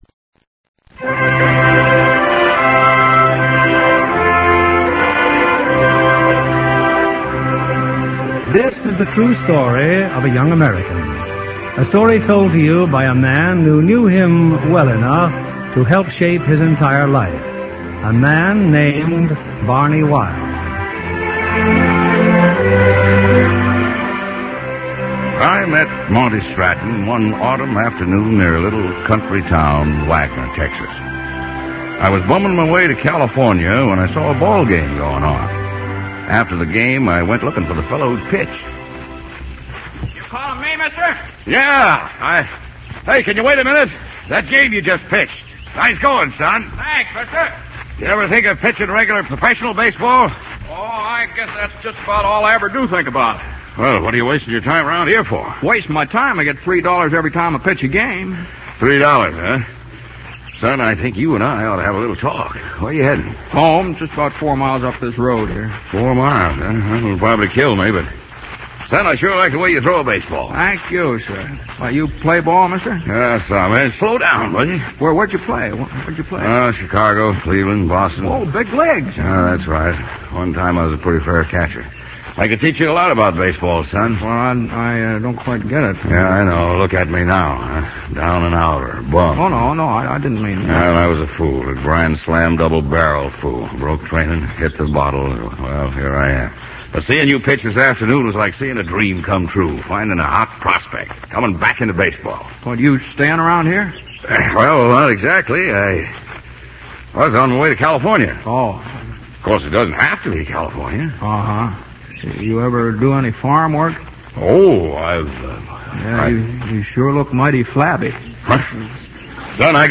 Lux Radio Theater Radio Show
Lux_50-02-13_The_Stratton_Story_No_Open_No_Close.mp3
The Stratton Story, starring James Stewart, June Allyson, John McIntire